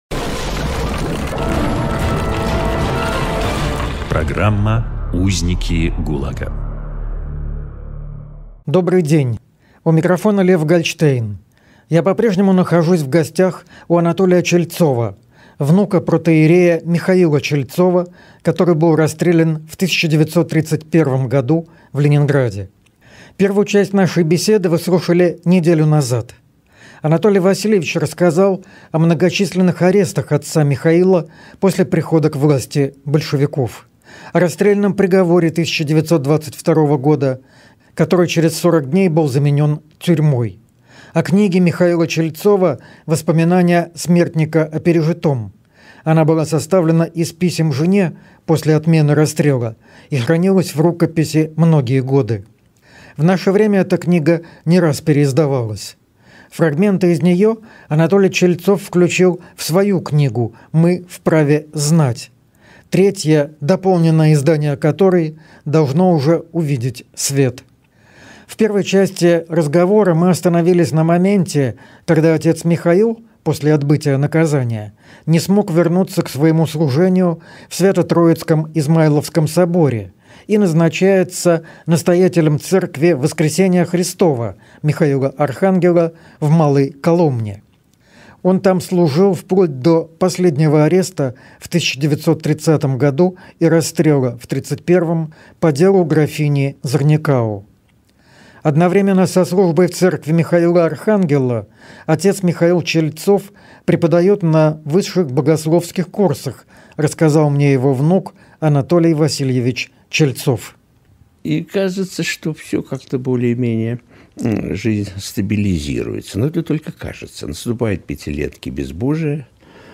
Запись эфира из архивов «Эха Петербурга»